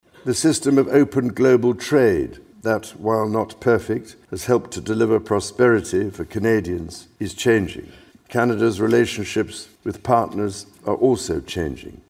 On Tuesday, His Majesty King Charles III delivered the speech from the throne in the Senate chamber, officially launching the first session of the 45th Parliament.
may27-kingsspeech-04.mp3